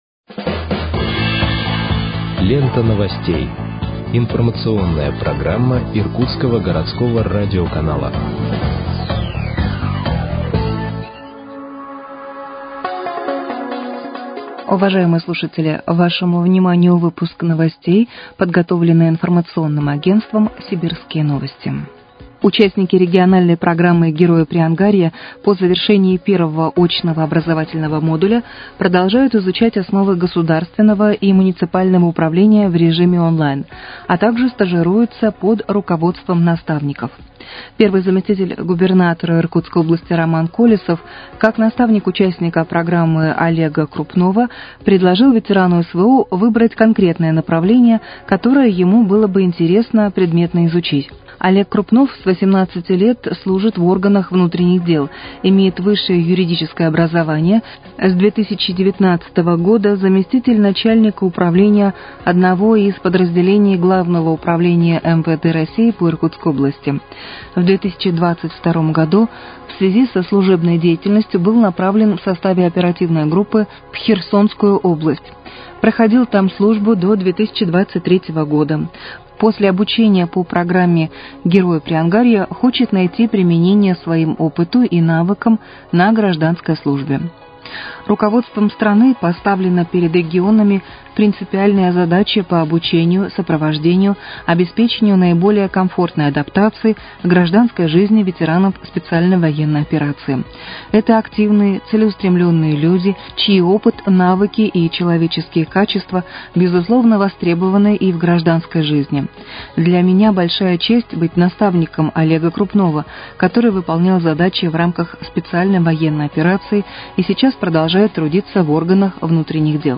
Выпуск новостей в подкастах газеты «Иркутск» от 21.08.2025 № 1